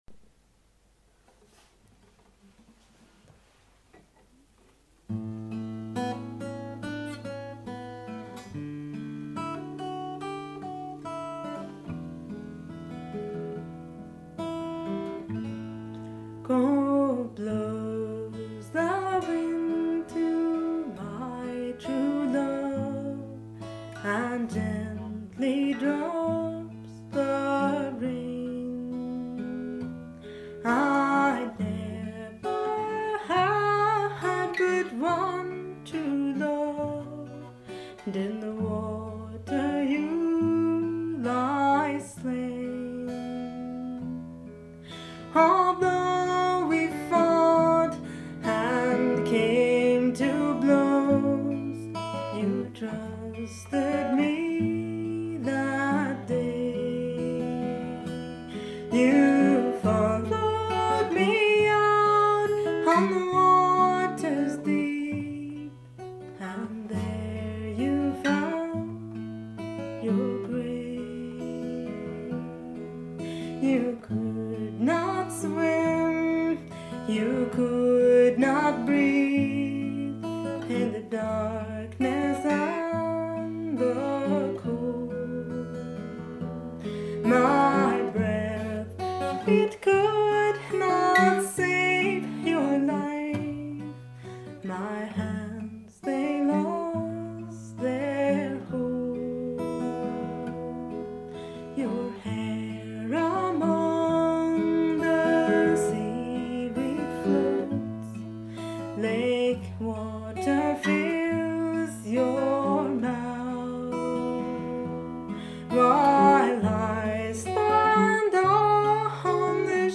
filk